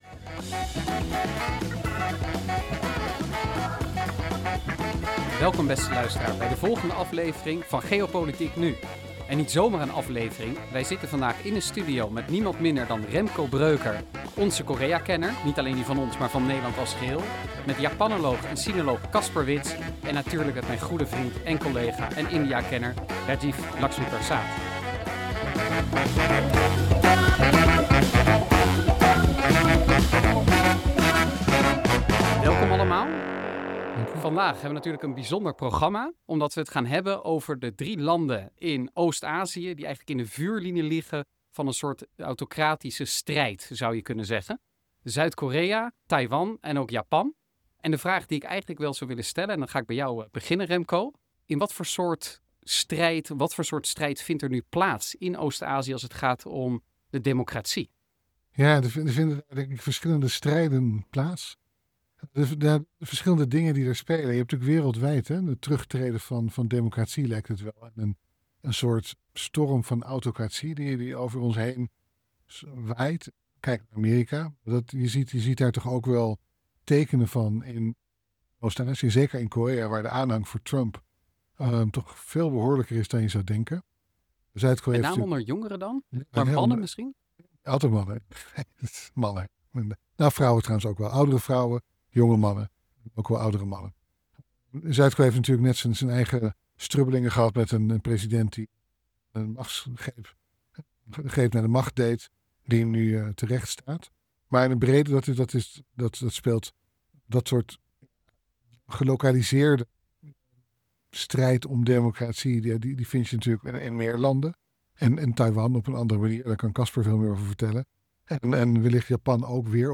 In deze aflevering van GeopolitiekNu schuiven twee HIG Azië analisten aan tafel